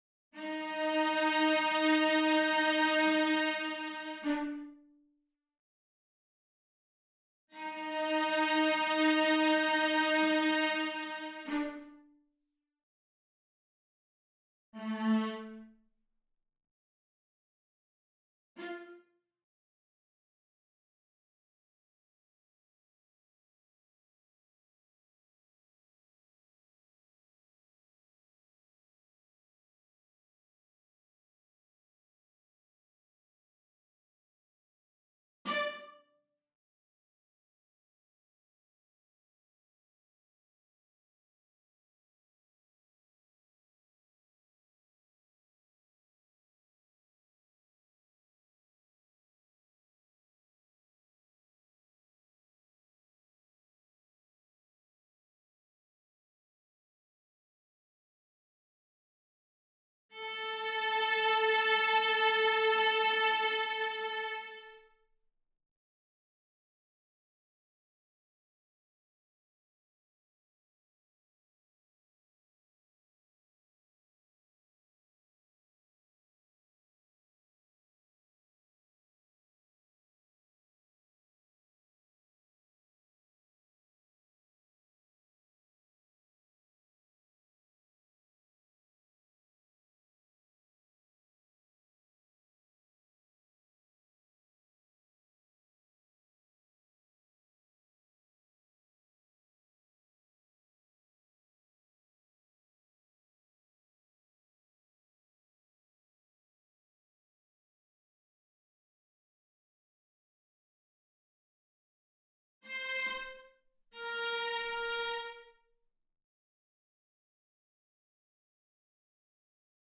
• reverb_mix = 30
1. Violin 1 (Violins section/Arco)
5. Viola (Violas section/Arco)
6. Cello (Cellos section/Arco)
7. Double bass (Contrabasses section/Arco)
8. Violin 2 Harmonics (Violin/Arco)
9. Viola Harmonics (Viola/Arco)
10. Cello Harmonics (Cello/Arco)